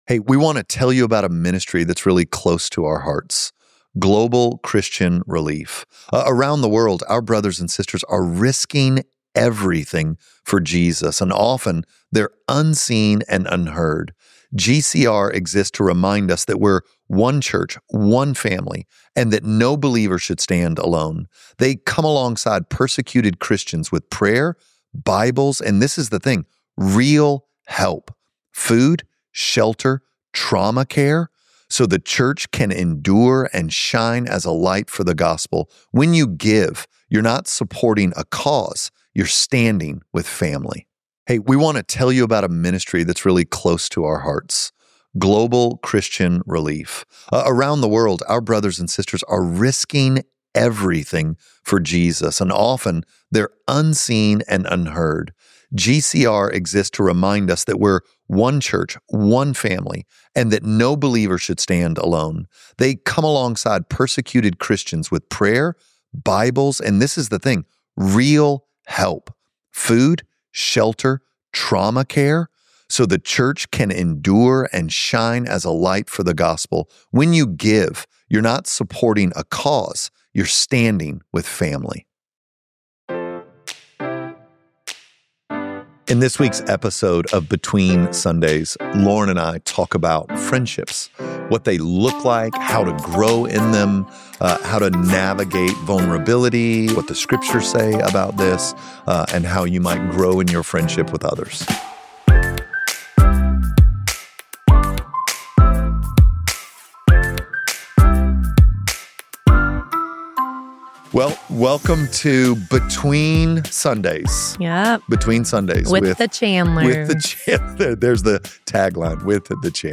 Our hope is that this conversation gives you vision and practical tools to form the kind